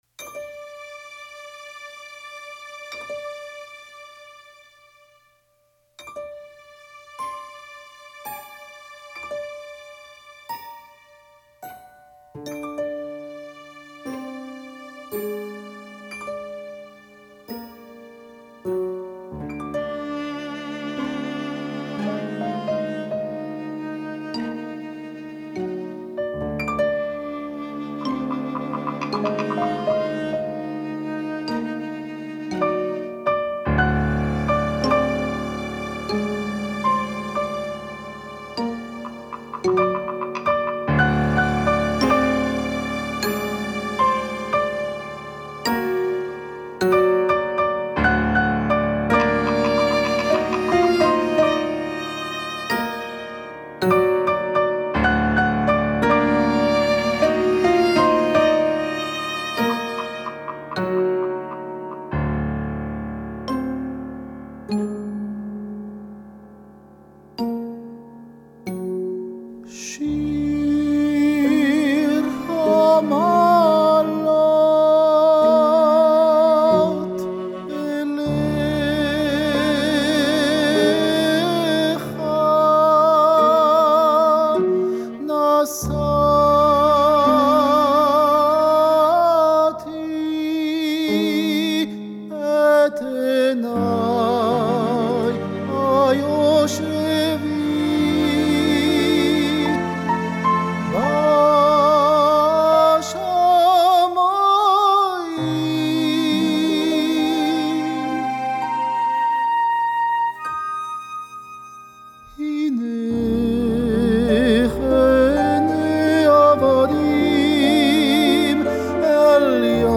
מזמורי שיר המעלות נעטפו במוסיקה שמימית ובקול הטנור הקריסטלי